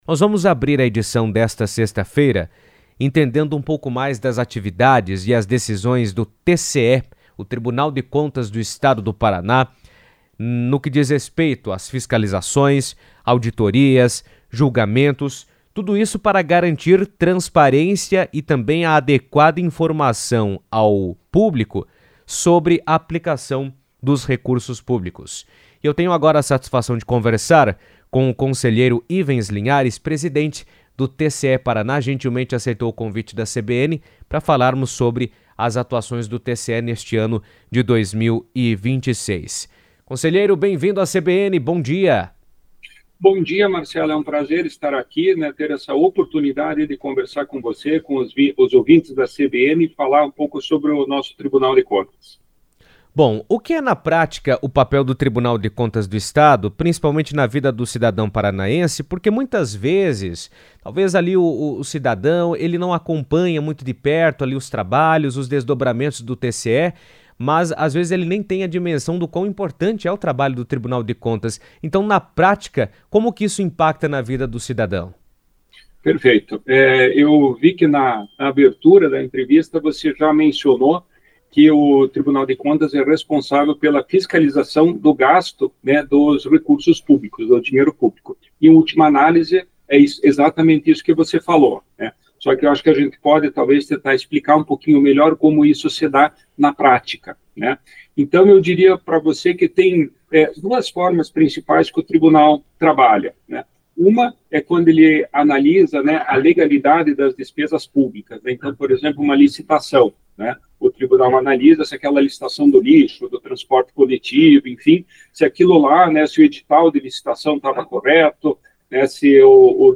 O Tribunal de Contas do Estado do Paraná (TCE/PR) reforça seu compromisso com a transparência e a correta aplicação dos recursos públicos por meio de fiscalizações, auditorias e julgamentos constantes. Em entrevista à CBN, o conselheiro Ivens Linhares destacou que essas atividades não apenas garantem a integridade da gestão pública, mas também oferecem informações claras e acessíveis à população sobre como os recursos do Estado são utilizados.